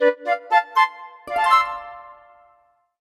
Âm thanh Tò mò, Tìm kiếm, Bối rối
Nhạc nền Tò mò, Bí ẩn Âm thanh Bối rối, Tò mò
Created by: Flute Curious Find
Thể loại: Hiệu ứng âm thanh
am-thanh-to-mo-tim-kiem-boi-roi-www_tiengdong_com.mp3